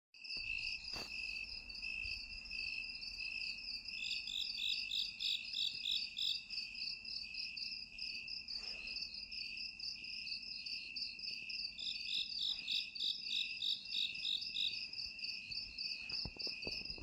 是比较熟悉的国内的山间声音 其实这种规律性的动物叫声也具有音乐性了
很像一些音乐前奏之前的虫鸣声。